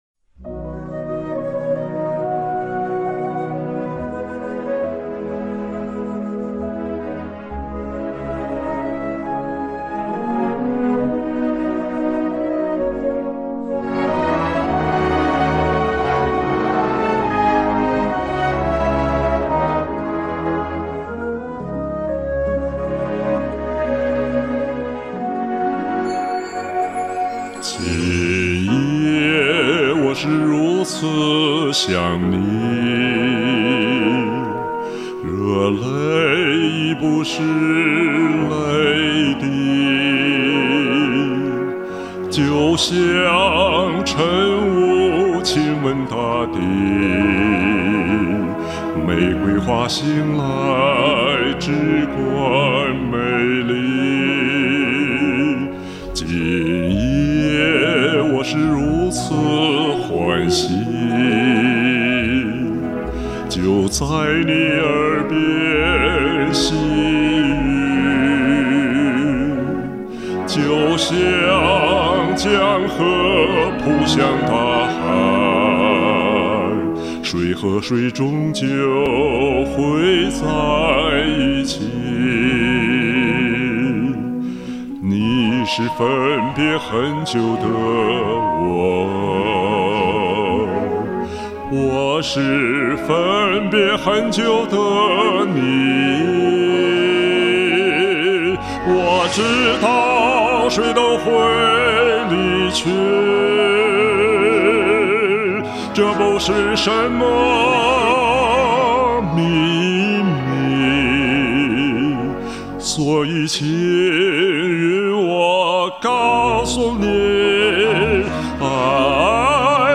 优美流畅，激情洋溢。
大地那里好低啊，低音太强了
这磁性的嗓音是一贯的好，感人至深的演唱！
浑厚的嗓音. 好听.